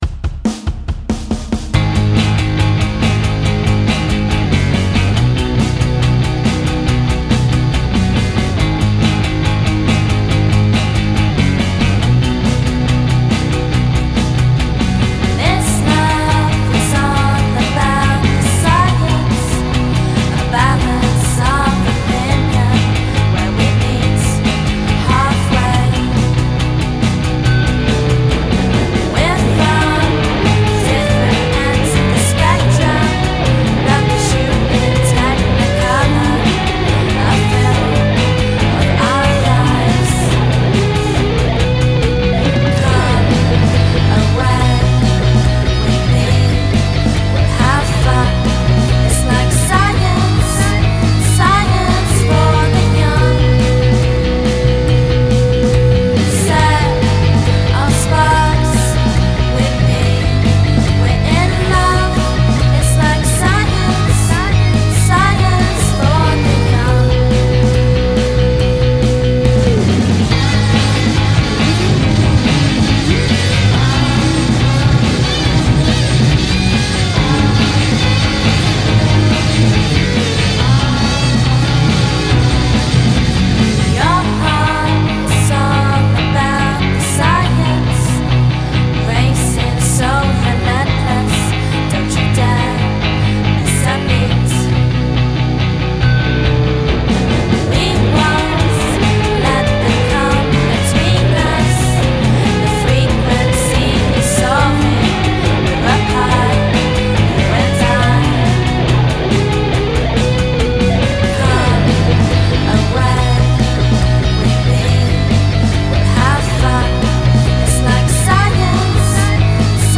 e con una forte voce femminile molto in primo piano.